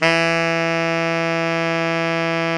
Index of /90_sSampleCDs/Roland L-CD702/VOL-2/SAX_Tenor V-sw/SAX_Tenor _ 2way
SAX TENORM05.wav